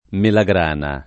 vai all'elenco alfabetico delle voci ingrandisci il carattere 100% rimpicciolisci il carattere stampa invia tramite posta elettronica codividi su Facebook melagrana [ mela g r # na ] s. f.; pl. melagrane (raro melegrane )